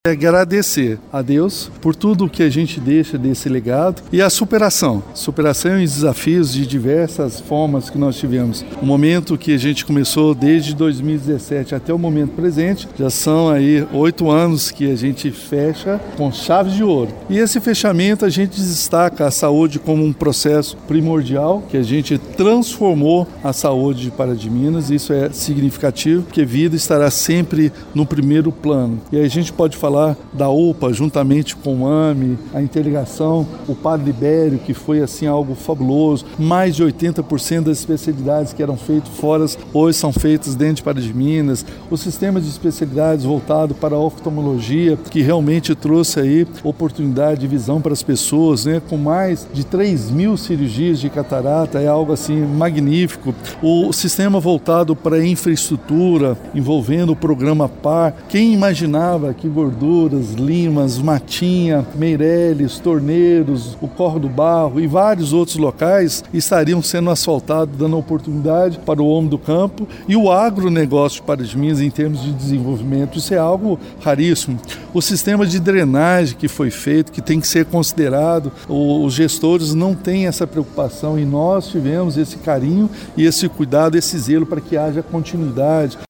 Em coletiva de imprensa, no Teatro Municipal, o Prefeito Elias Diniz fez uma apresentação das principais ações da administração municipal em 2024.